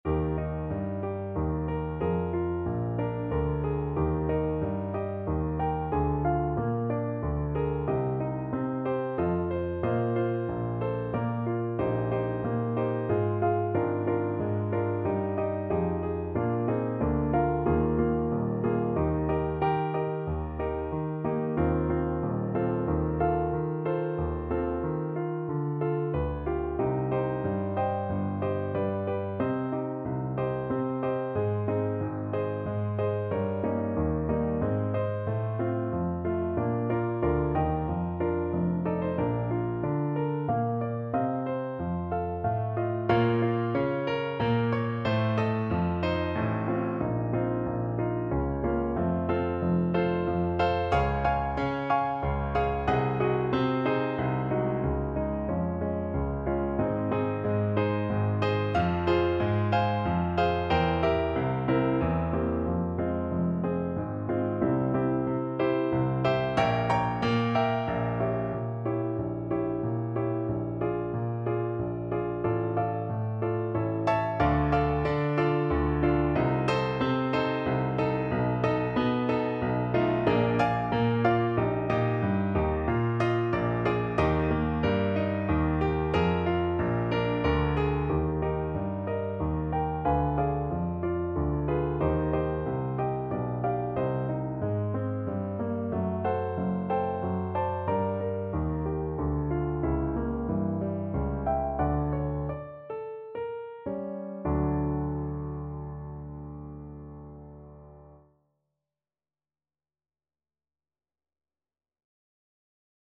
Play (or use space bar on your keyboard) Pause Music Playalong - Piano Accompaniment Playalong Band Accompaniment not yet available reset tempo print settings full screen
Eb major (Sounding Pitch) Bb major (French Horn in F) (View more Eb major Music for French Horn )
3/4 (View more 3/4 Music)
~ = 92 Larghetto